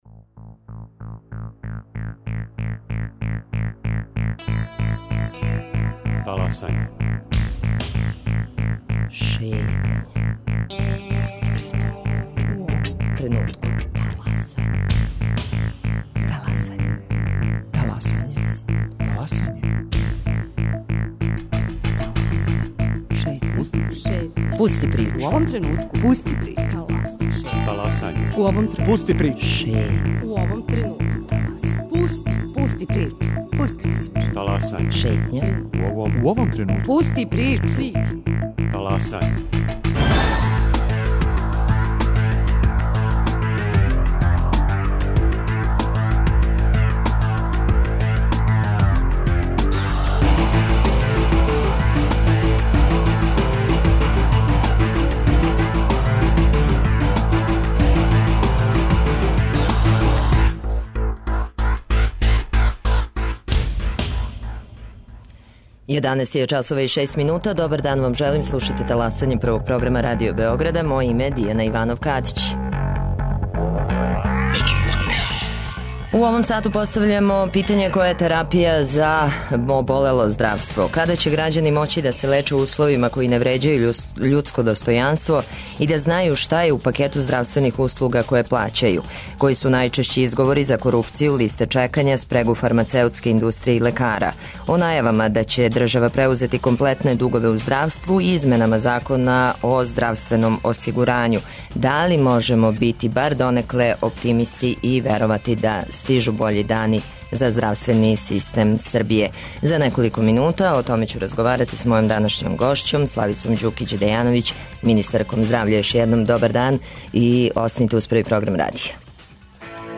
Гошћа: Славица Ђукић Дејановић, министарка здравља.